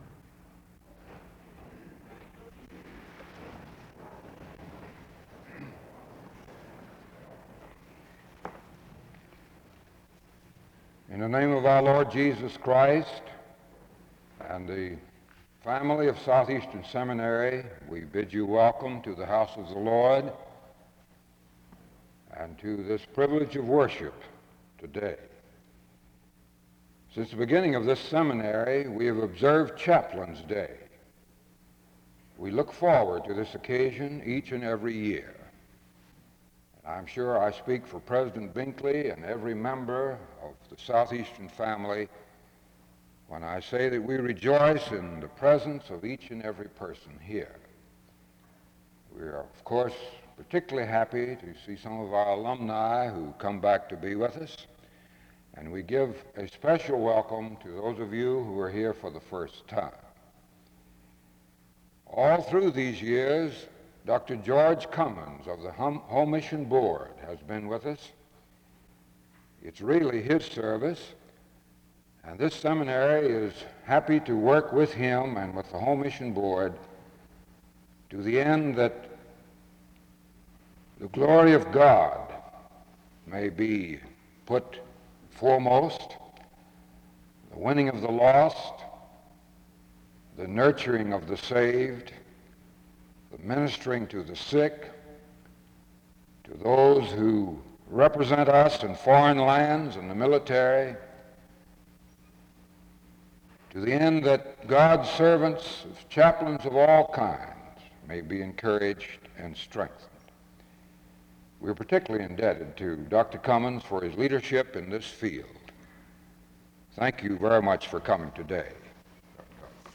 Download .mp3 Description Francis Sampson was a Catholic priest and Chaplain (Major General), Army Chief of Chaplains. Reverend Sampson was accompanied by other chaplains at this chapel service as SEBTS were recognizing many of those who served. Rev. Sampson taught about the importance of how Christians should recognize that they are in a constant state of war with the world and sin and how it is our duty to fight and encourage the next generation as well.